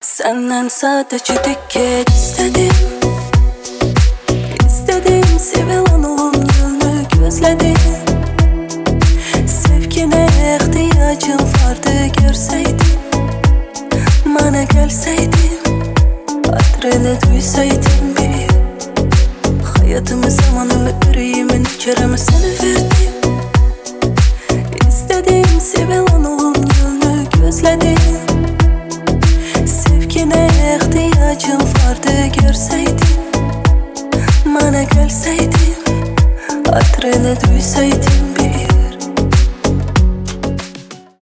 ремиксы
deep house